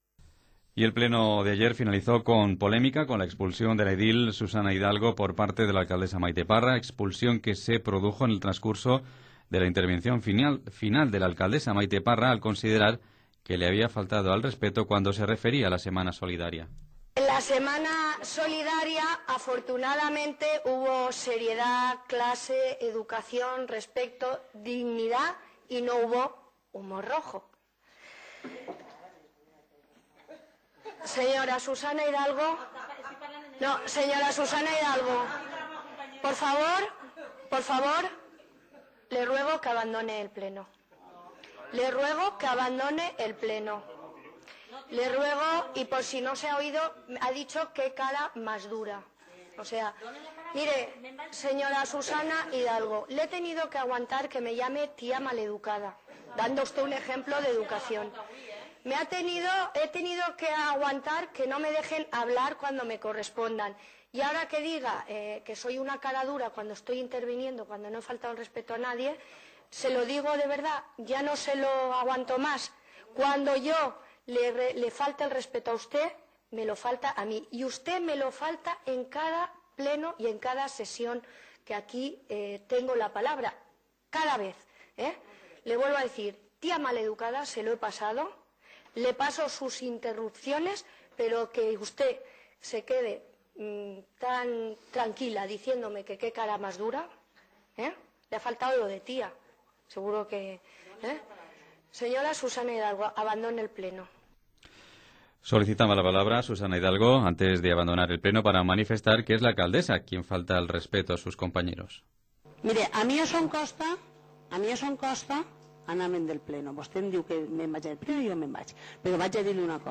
Lo que ocurrió en el Pleno – Escucha un extracto de las noticias en Radio Ibi pinchando aquí.